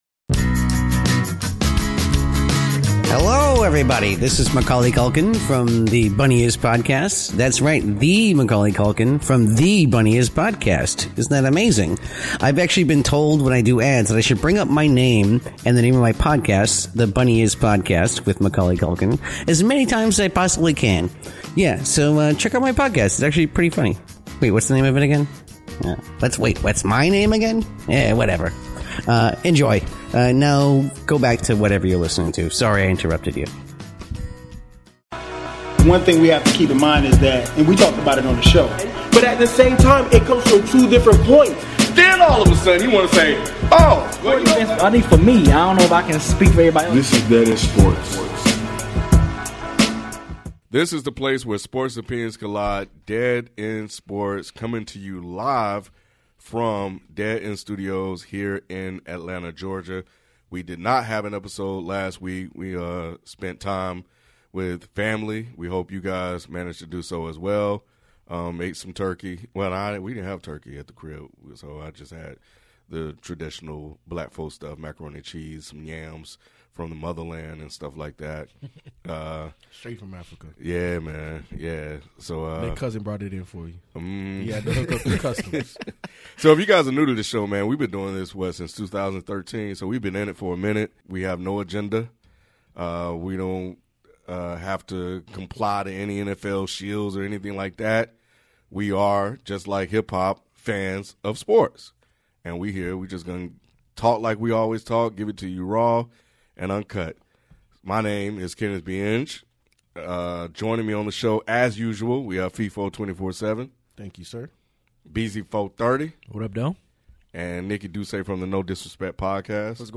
Our favorite part of recording a live podcast each week is participating in the great conversations that happen on our live chat, on social media, and in our comments section.